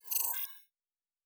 pgs/Assets/Audio/Sci-Fi Sounds/Weapons/Additional Weapon Sounds 2_2.wav at master
Additional Weapon Sounds 2_2.wav